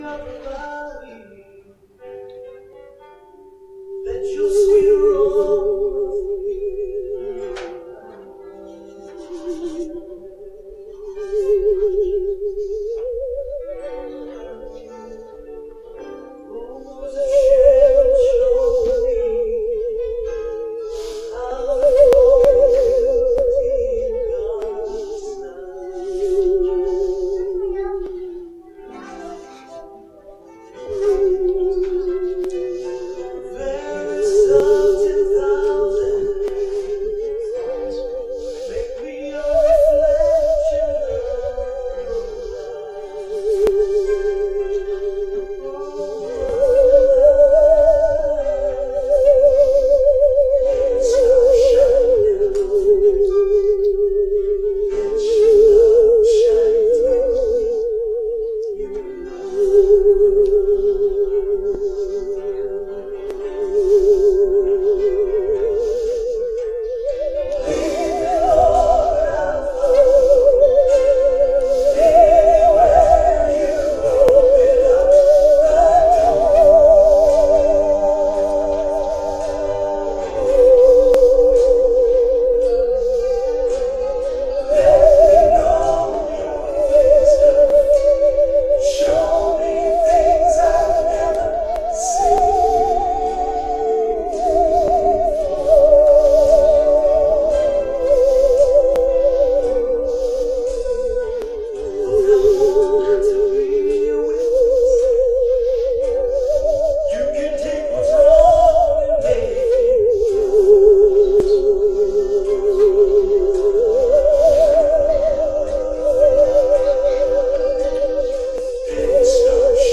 Special performances